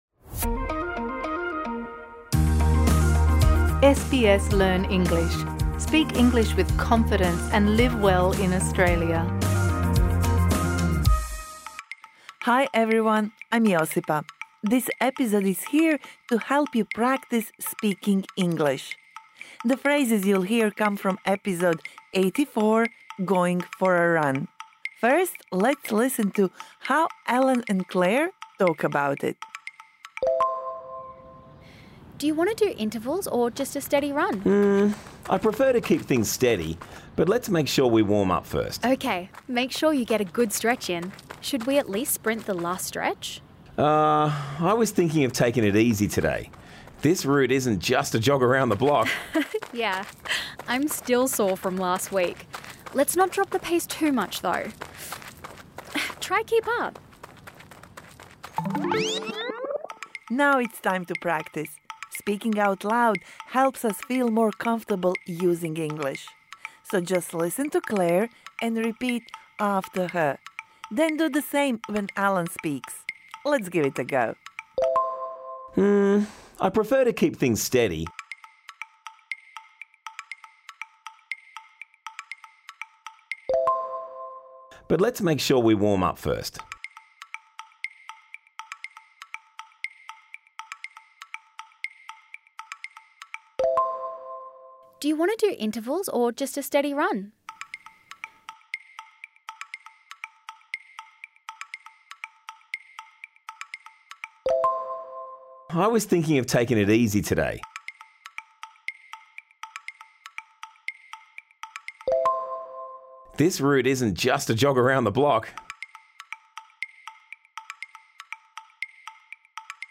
This bonus episode provides interactive speaking practice for the words and phrases you learnt in Episode #84 Going for a run (Med) Don't be shy - just try!